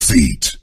geometry dash feet (defeat) sfx sound effect